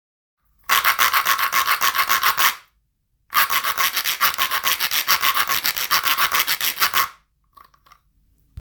●シェイカー・小物・笛
丸い実をリズムをとりながら左手で上下、ジュジュの実(コゲチャ)を棒に触れたり離したり、共鳴体として使用します。
素材： 木・実